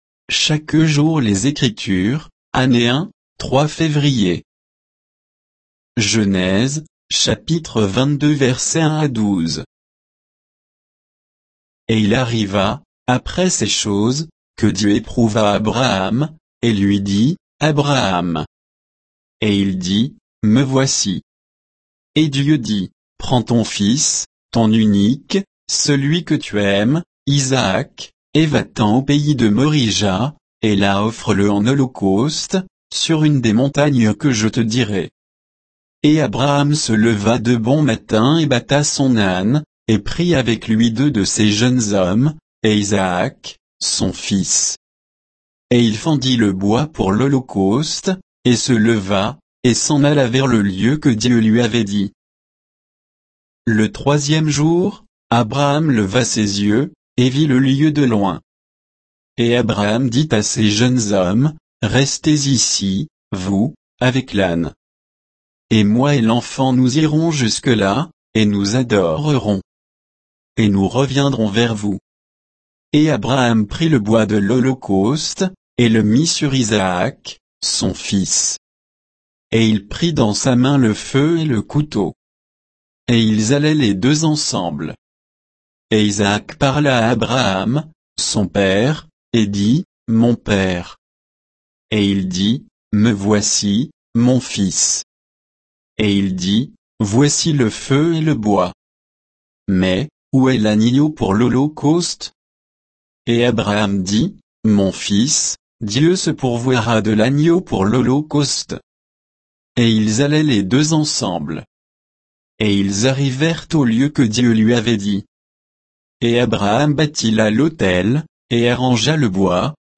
Méditation quoditienne de Chaque jour les Écritures sur Genèse 22, 1 à 12